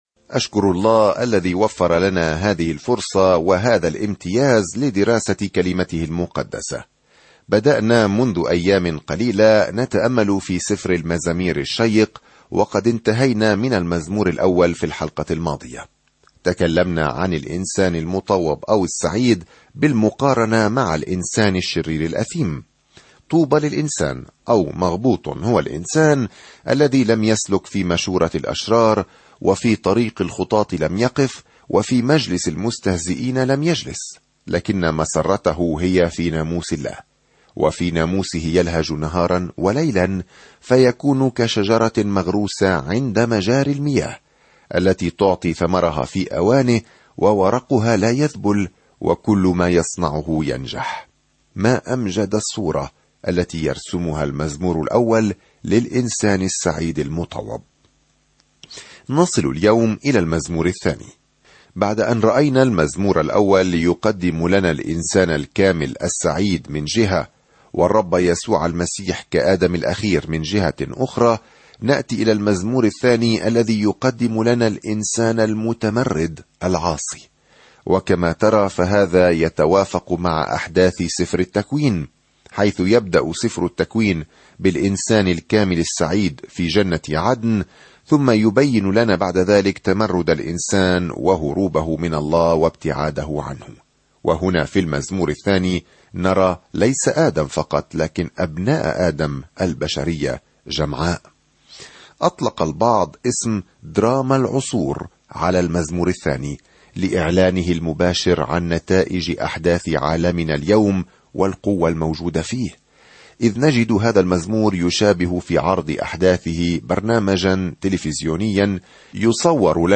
سافر يوميًا عبر المزامير وأنت تستمع إلى الدراسة الصوتية وتقرأ آيات مختارة من كلمة الله.